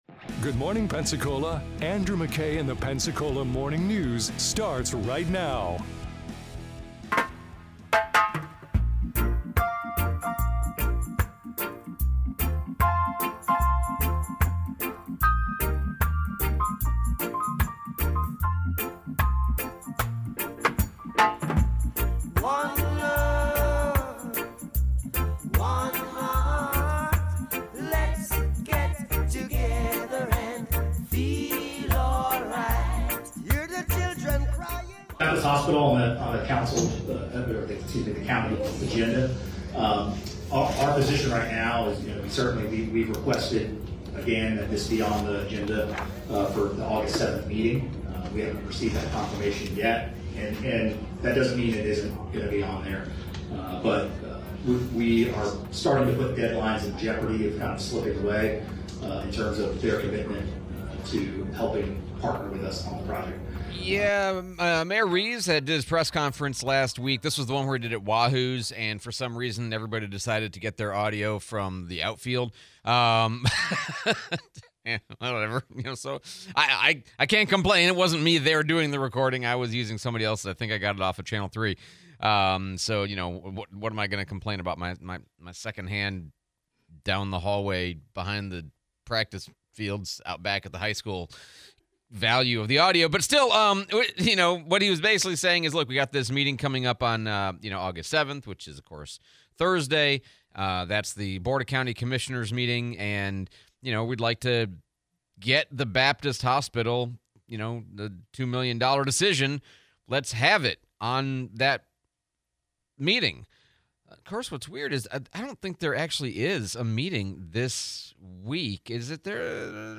Baptist Hospital, Replay of Sheriff Bob Johnson interview